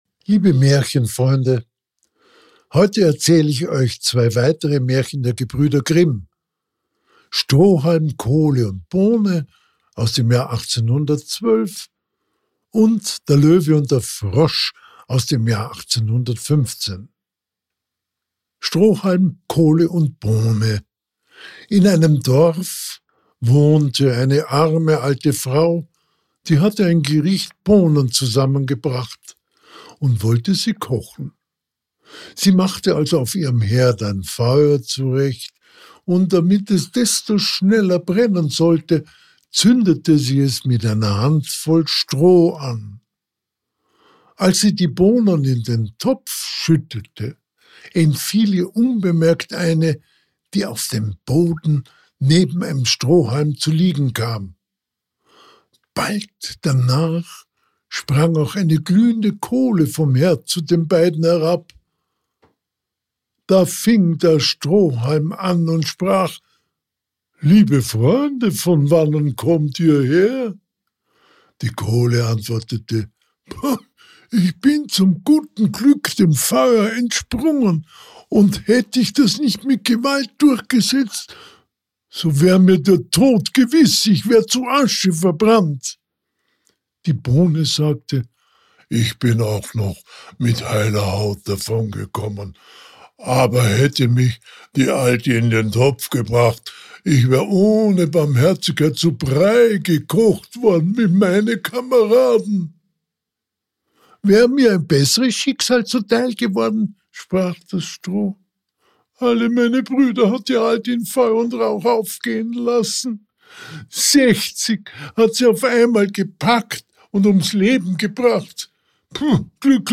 Liebe Märchenfreunde, heute erzähle ich Euch 2 weitere Märchen der Gebrüder Grimm: Strohalm, Kohle und Bohne aus dem Jahr 1812, sowie der Löwe und der Frosch aus dem Jahr 1815.